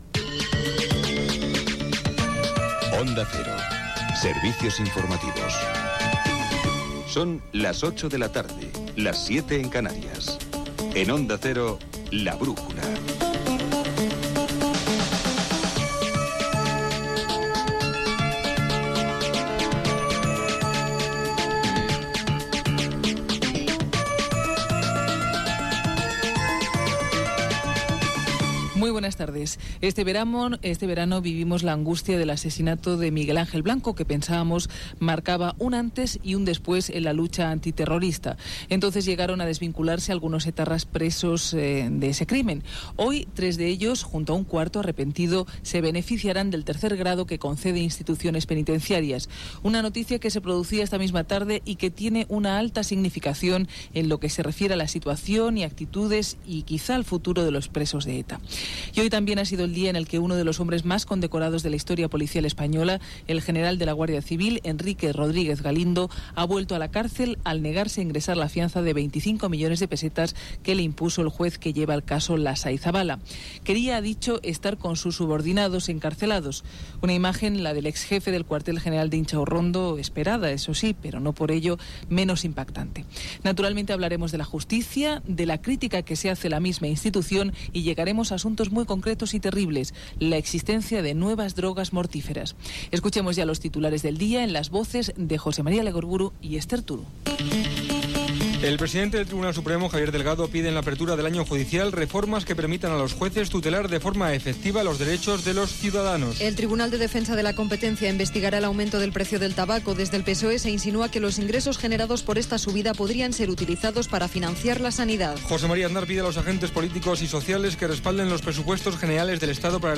Careta del programa, titulars informatius, hora, indicatiu del programa, concessió del règim obert de presó a quatre integrants de la banda ETA, ingés a presó del general de la Guardia Civil Rodríguez Galindo
Informatiu